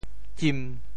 「吣」字用潮州話怎麼說？
吣（吢） 部首拼音 部首 口 总笔划 7 部外笔划 4 普通话 qìn 潮州发音 潮州 zim1 文 cim3 文 中文解释 唚 <動> [貓、狗] 嘔吐 [(of dog or cat) vomit] 吐酒猶如貓狗唚,好土空把塹坑填。